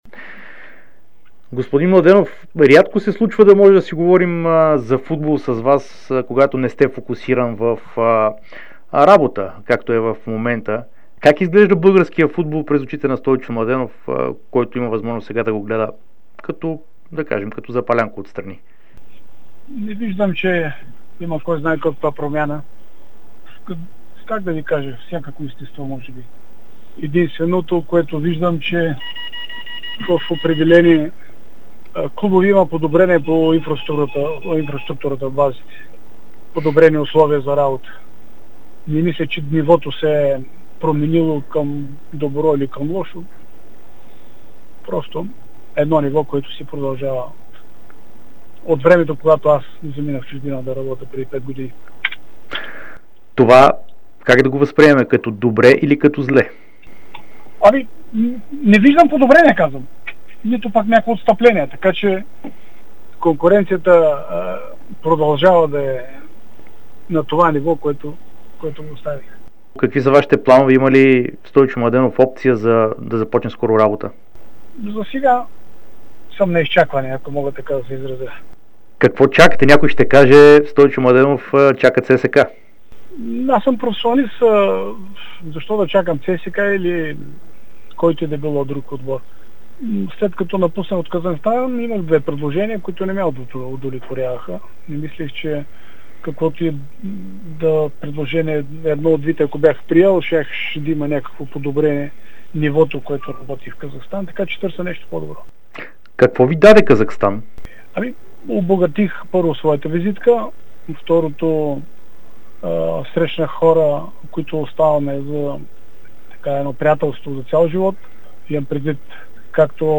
Бившият треньор на ЦСКА Стойчо Младенов даде обширно интервю пред Дарик и dsport, в което сподели, че не вижда коренна промяна във футбола у нас от последния път, в който той работи в България.